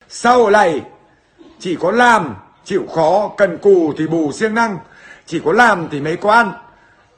Thể loại: Câu nói Viral Việt Nam
Âm thanh này đang hot trên các trang mạng xã Facebook, TikTok,... Đó là câu nói nổi tiếng của Huấn Hoa Hồng.